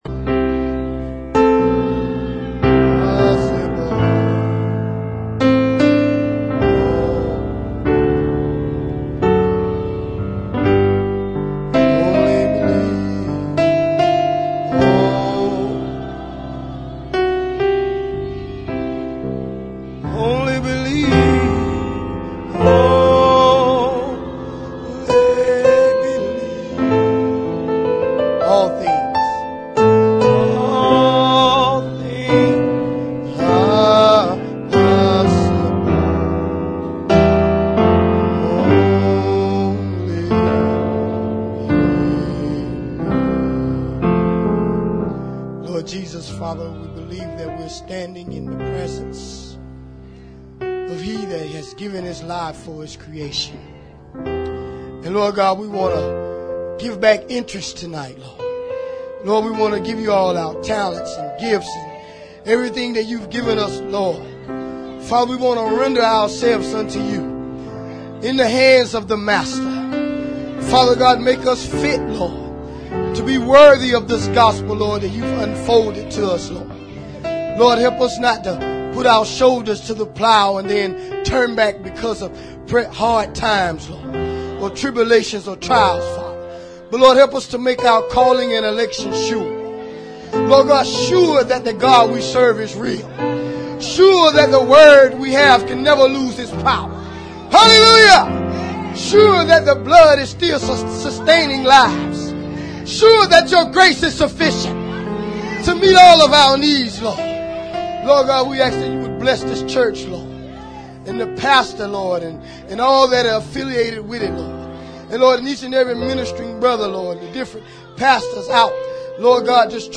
Please REGISTER or LOG-IN to LiveStream or View Archived Sermons.